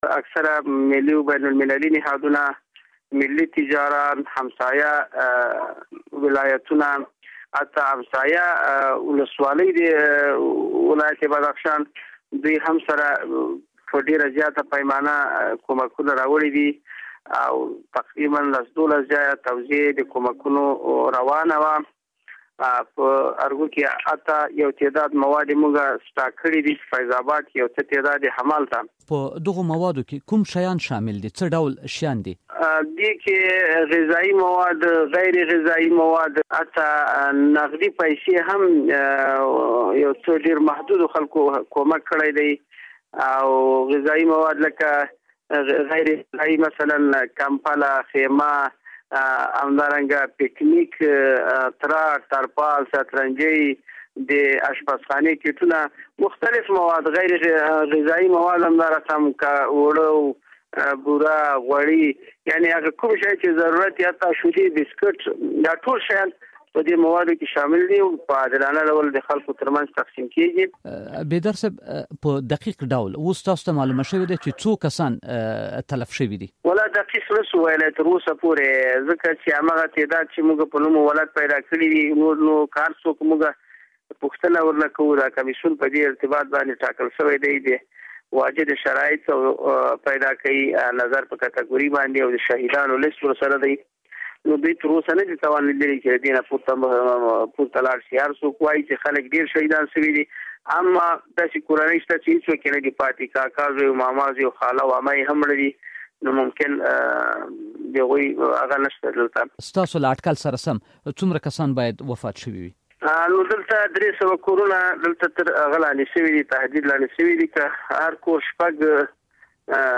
د بدخشان د والي له مرستیال ګل محمد بیدار سره مرکه